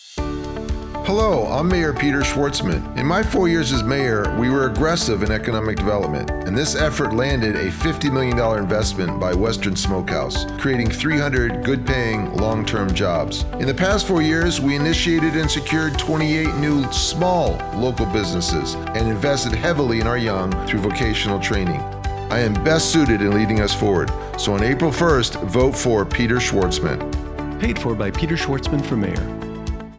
RADIO ADS: